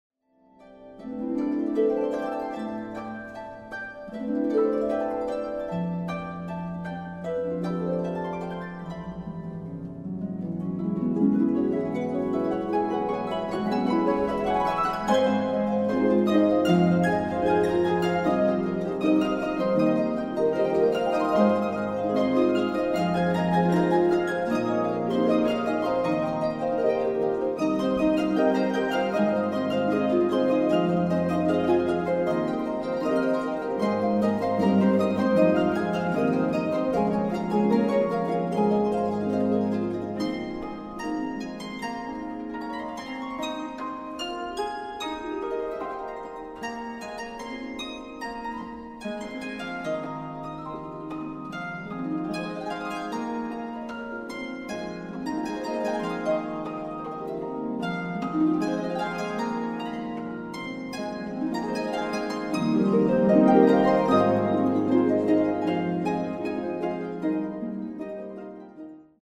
for 8 or 16 harps